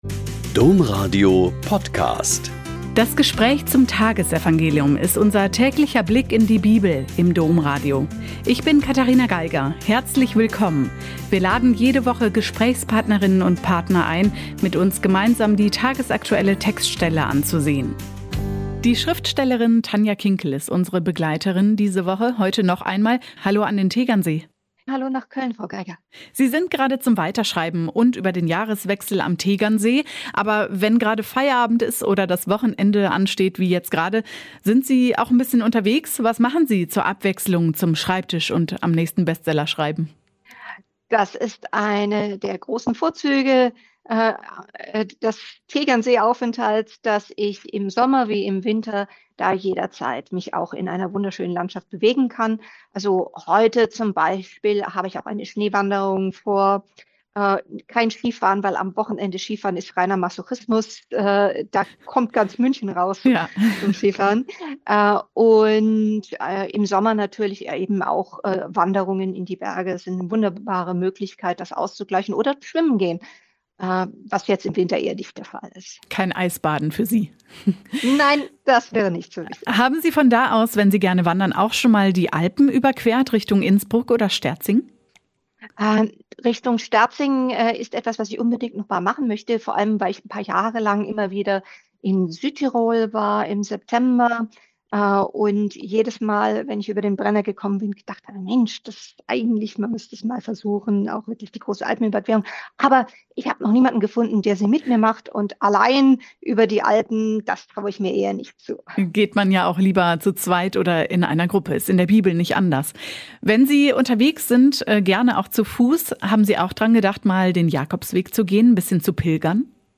Joh 1,35-42 - Gespräch mit Tanja Kinkel